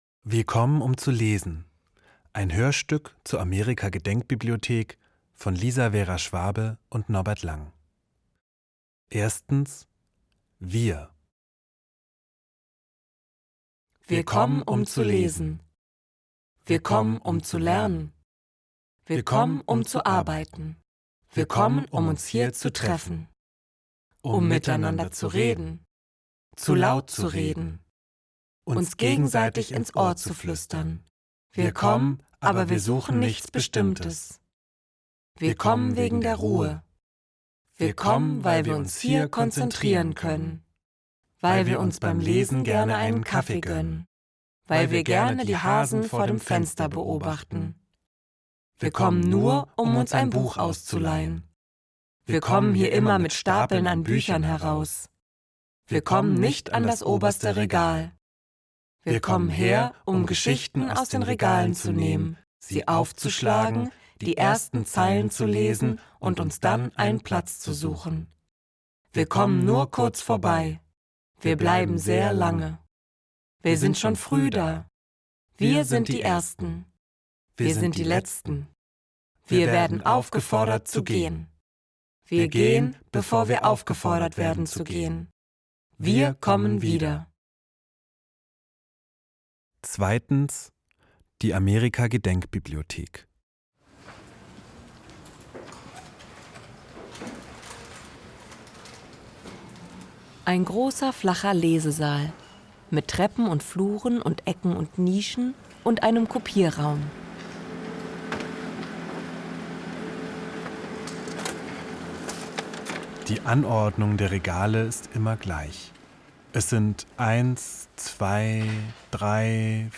Ein Hörspiel über die Amerika-Gedenkbibliothek, Berlin, 2019